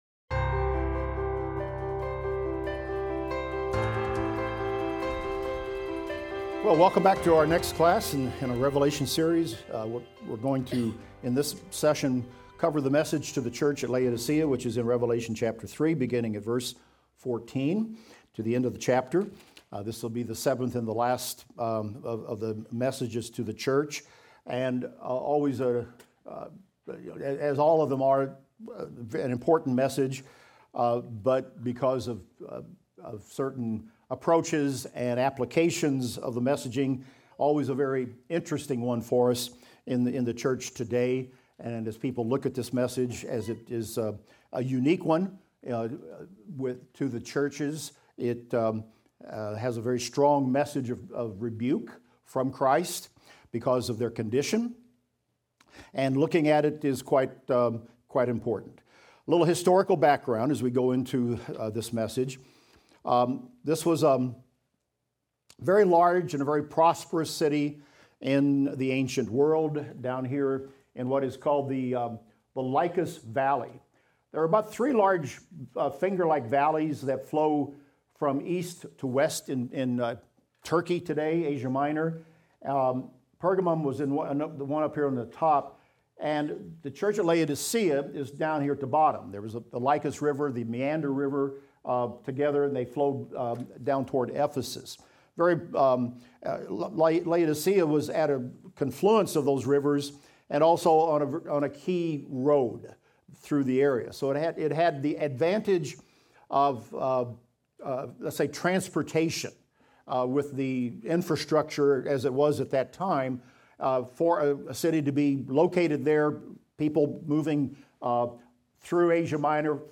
Revelation - Lecture 34 - Audio.mp3